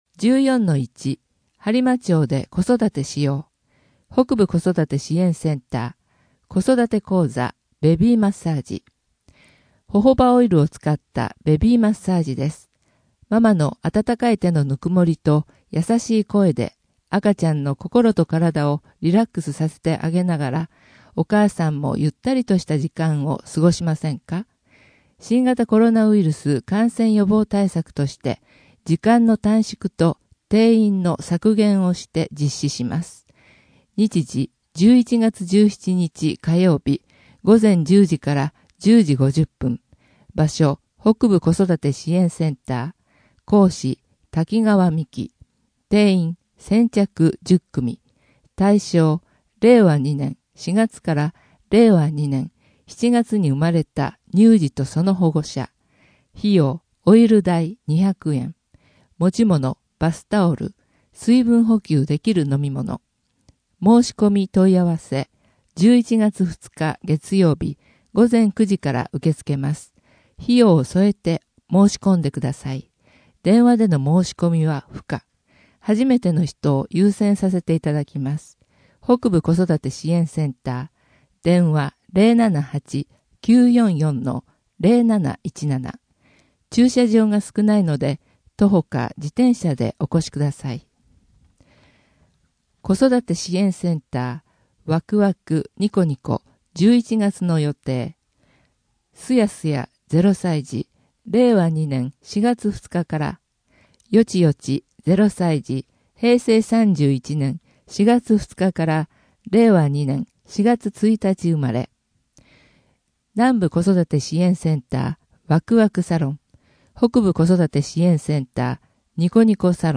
声の「広報はりま」11月号
声の「広報はりま」はボランティアグループ「のぎく」のご協力により作成されています。